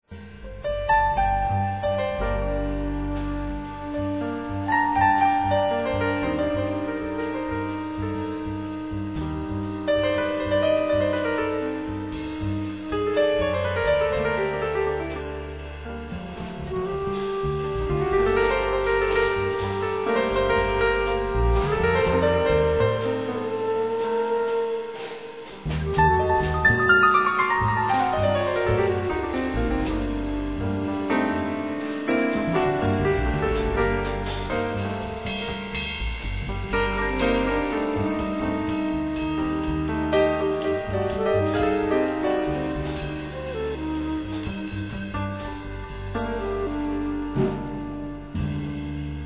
Saxphone,Flute
Piano
Drums
Double bass